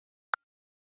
В подборке — короткие и узнаваемые сигналы, которые помогут настроить мессенджер под ваш стиль.
Звук уведомления в Viber